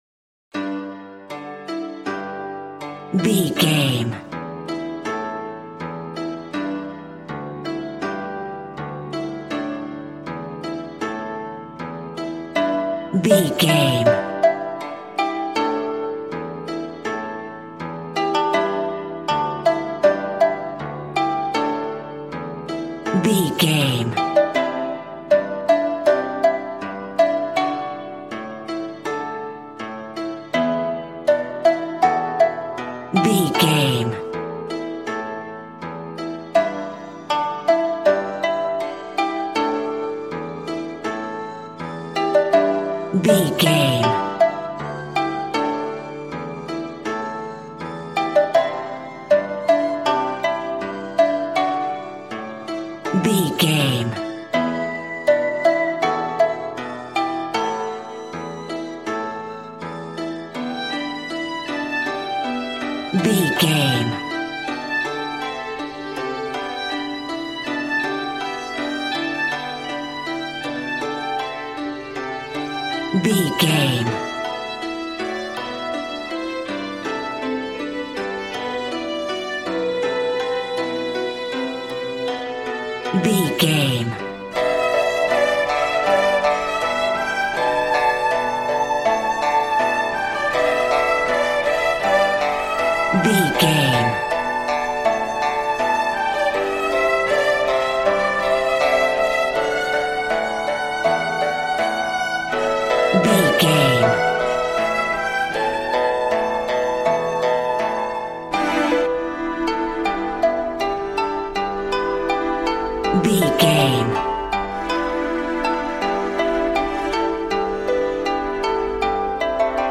Aeolian/Minor
happy
bouncy
conga